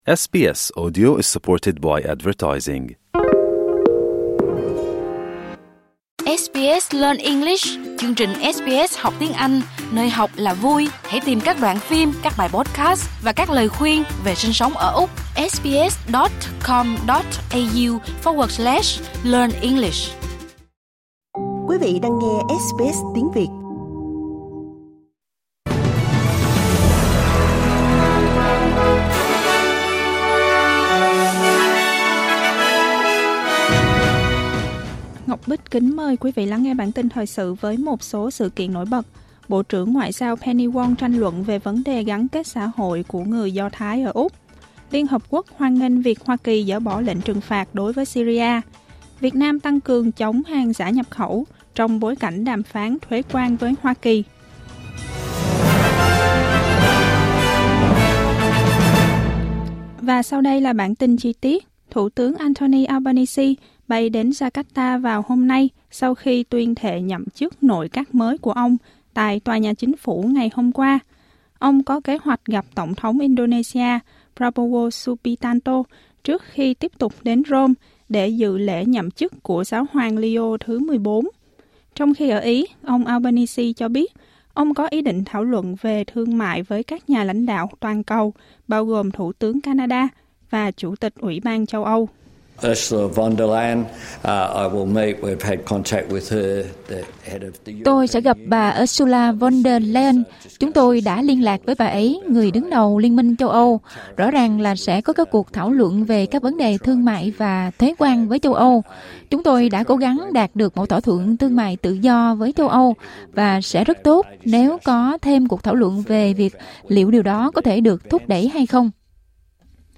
Một số tin tức trong bản tin thời sự của SBS Tiếng Việt.